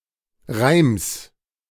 [ʁaɪ̯ms](info)) isch ä Schtadt im Nordoschte vo Frankriich, öbbe 140 km vo Baris.